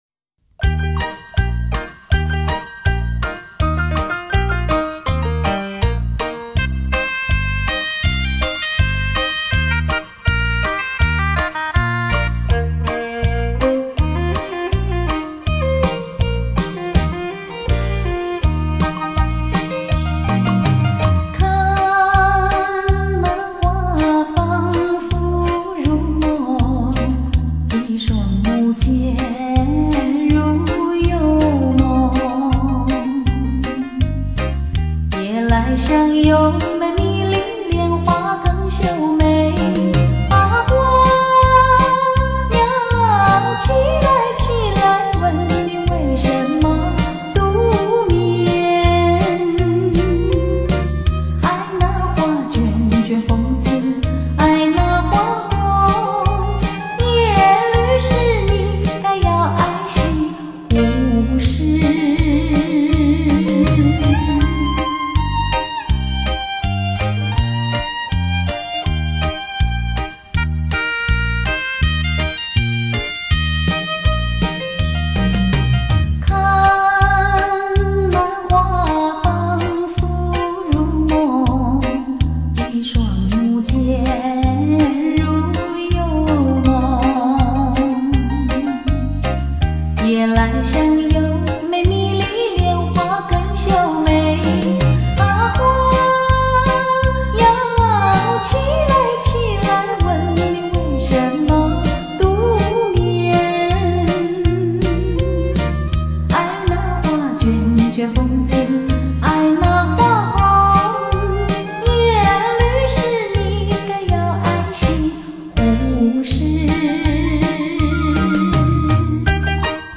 吉鲁巴